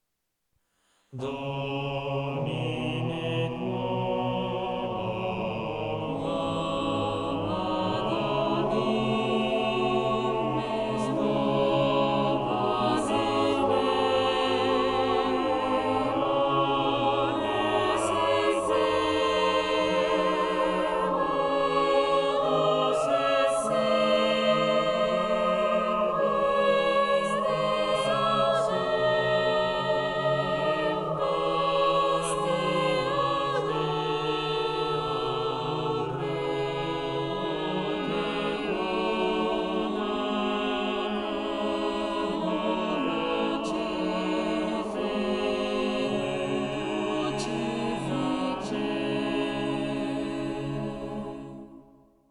A three-voice canon at the unison (except for the tenor, which starts an octave lower than the upper voices) with text from the Vulgate as placeholder lyrics. The bass constitutes its own independent line.